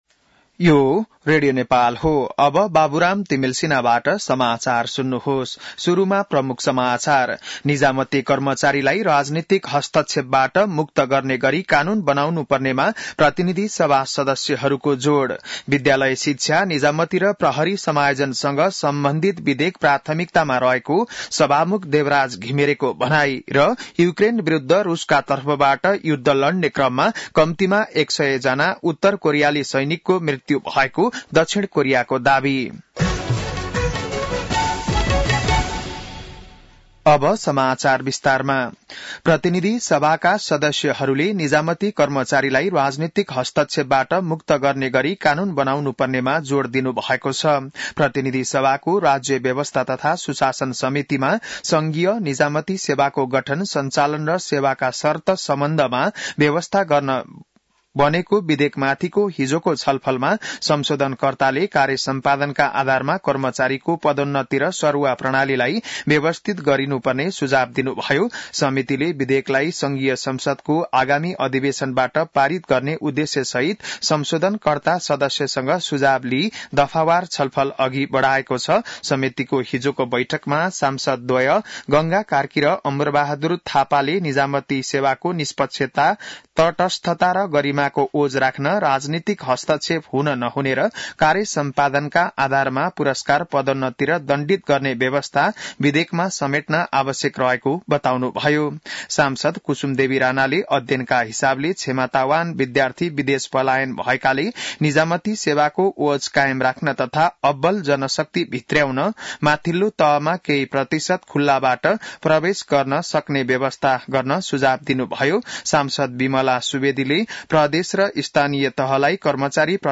बिहान ९ बजेको नेपाली समाचार : ६ पुष , २०८१